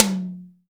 • Wet Tom Drum Single Hit F# Key 11.wav
Royality free tom single hit tuned to the F# note. Loudest frequency: 4576Hz
wet-tom-drum-single-hit-f-sharp-key-11-03T.wav